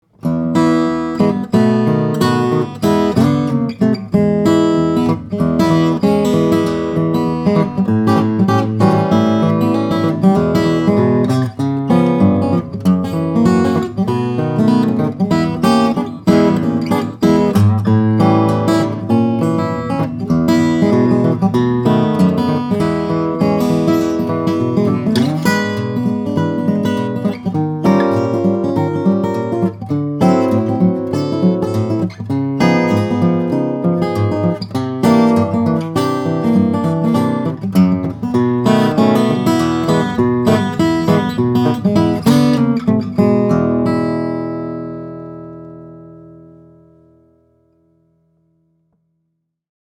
The warmth, vigor, and bright tone of this 00 is very impressive; the guitar has completely opened up, given its almost centenarian status.